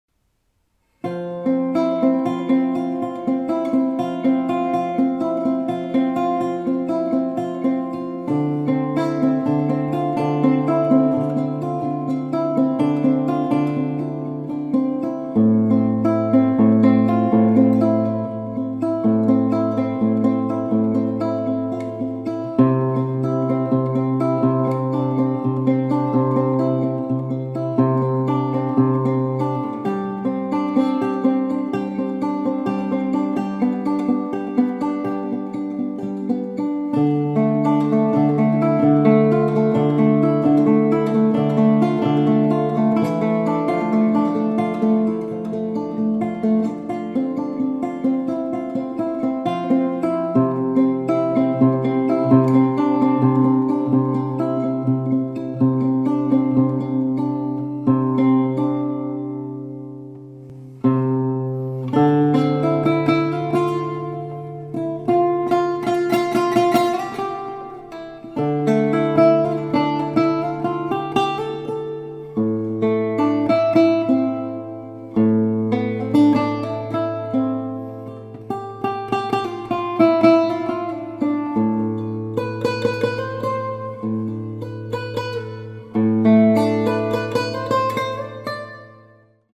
mandora/lute guitar